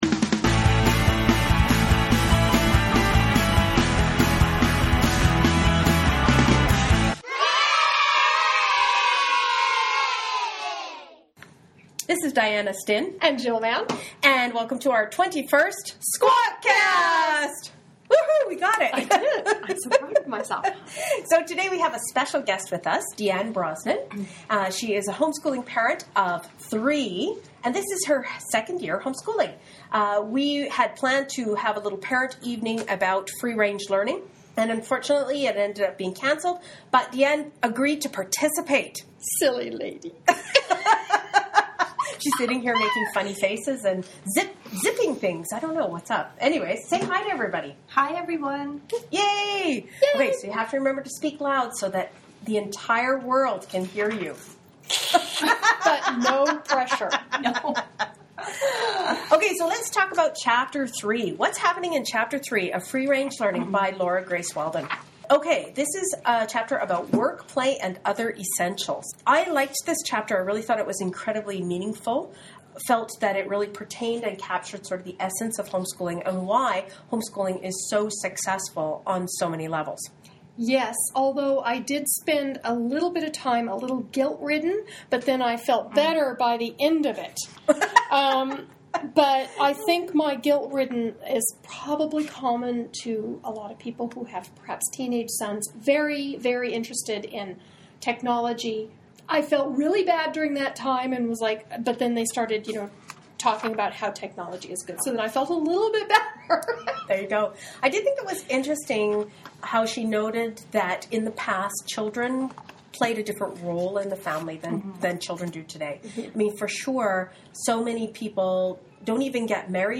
new homeschooling mom